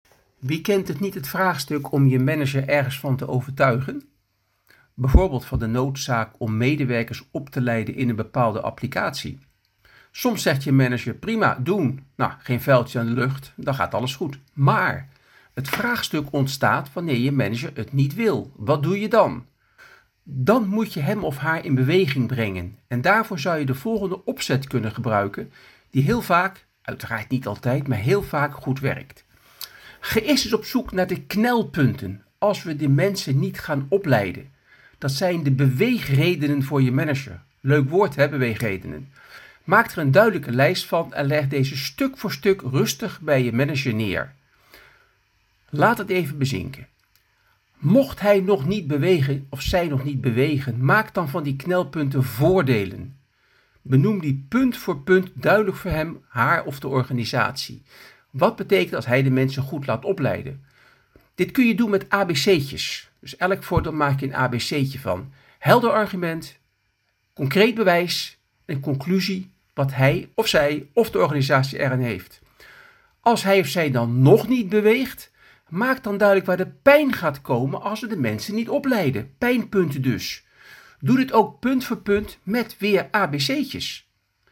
Podcast opgenomen tijdens Succestival 2023.